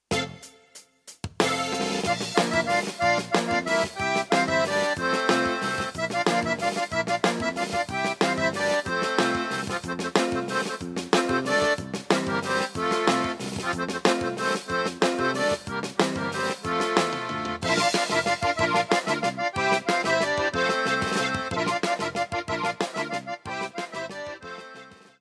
21-Huapango-Norteno.mp3